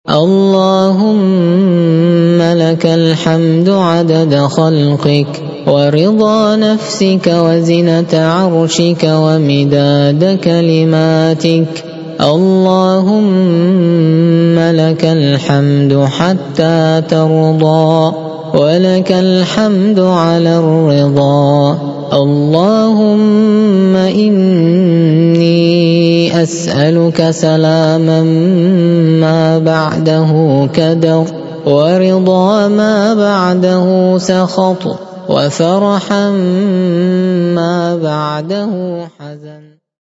ادعية